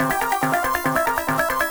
Index of /musicradar/8-bit-bonanza-samples/FM Arp Loops
CS_FMArp A_140-C.wav